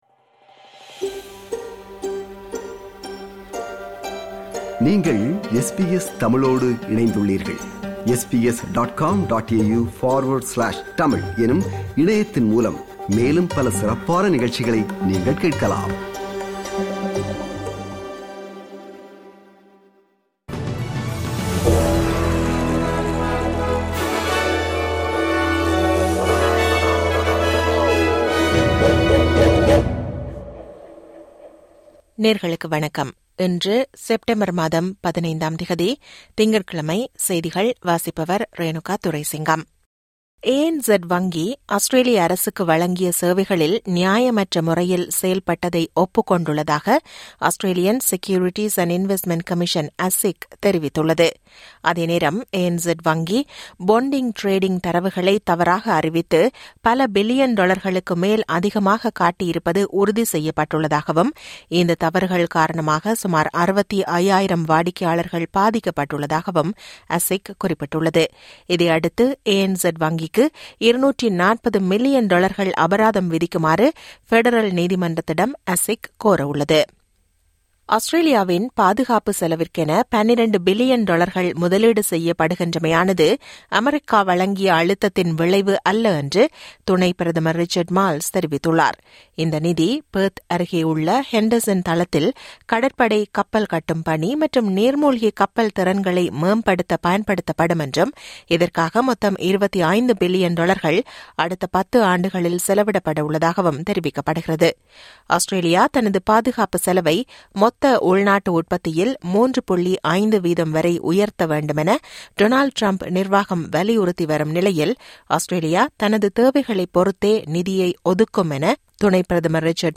இன்றைய செய்திகள்: 15 செப்டம்பர் 2025 திங்கட்கிழமை
SBS தமிழ் ஒலிபரப்பின் இன்றைய (திங்கட்கிழமை 15/09/2025) செய்திகள்.